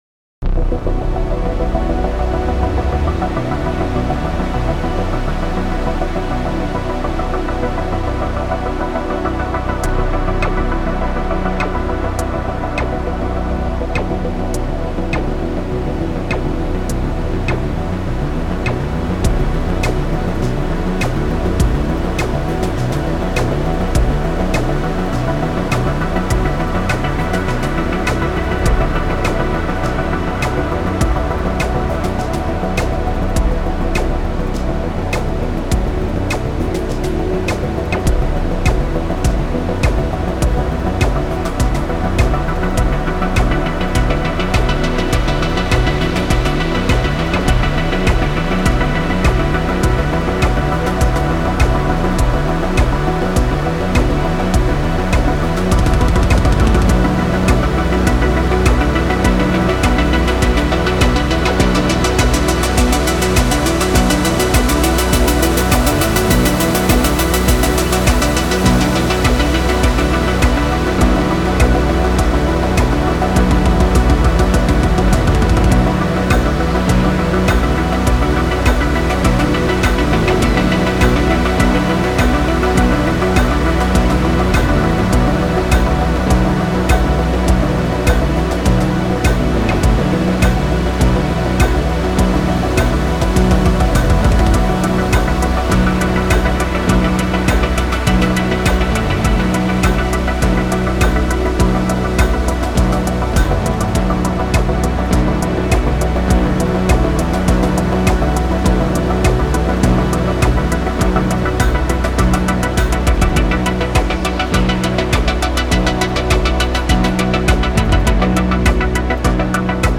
suspenseful film score